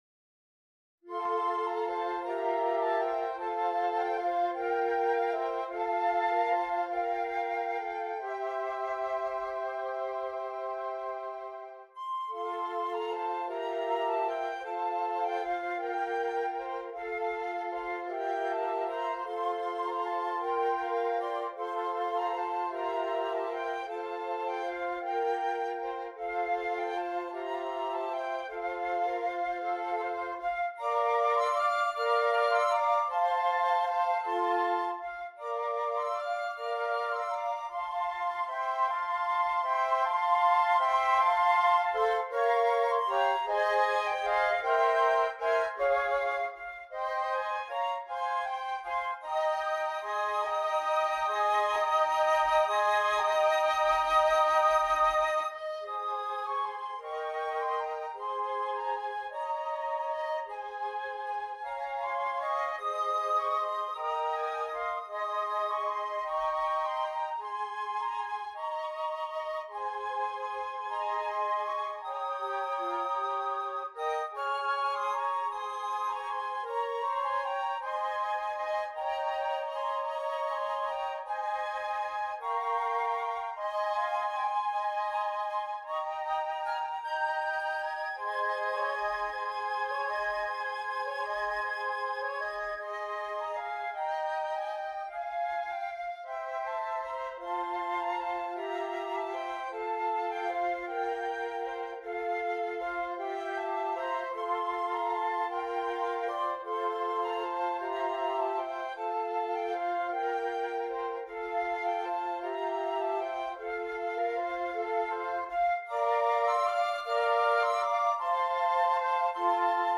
6 Flutes
This beautiful and flowing Christmas carol